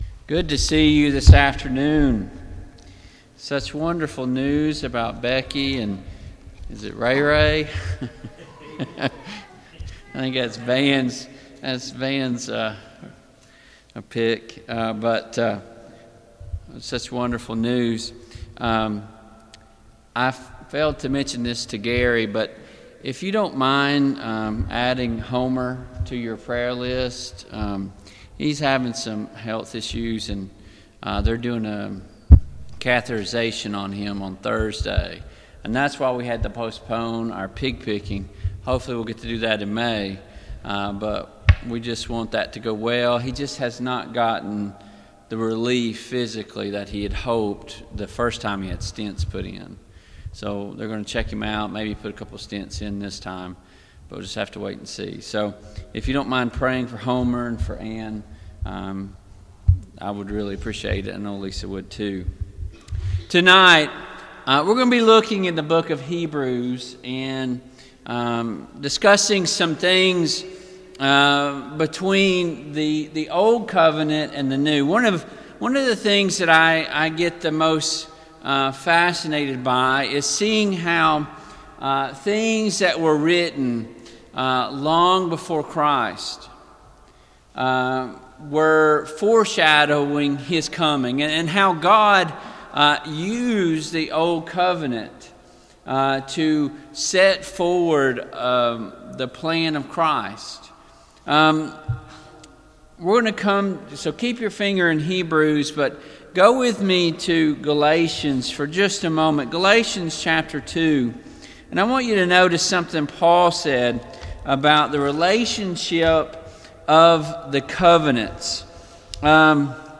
Service Type: PM Worship Topics: The Blood of Christ , The Church , The Old Covenant vs The New Covenant , The Tabernacle